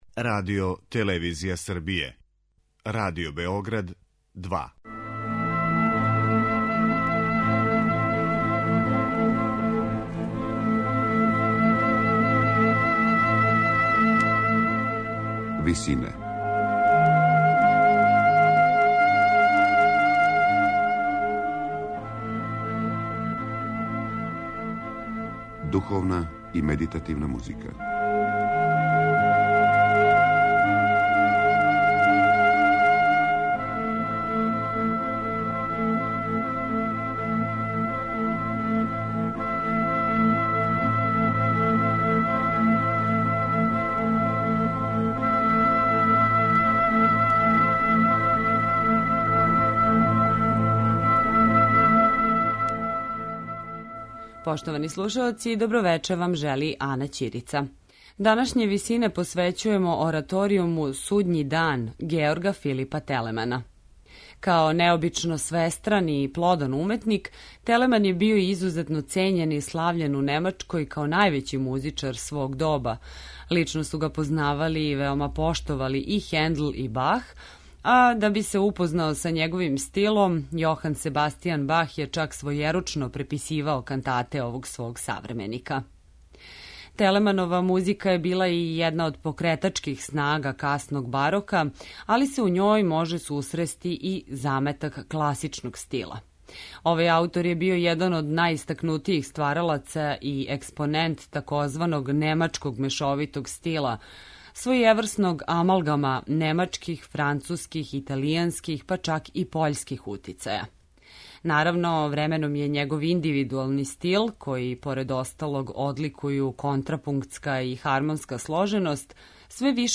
Остварење Судњи дан из 1762. године, написано за солисте, хор, оркестар и континуо, представља последњи Телеманов ораторијум у којем се највише огледају утицаји Георга Фридриха Хендла.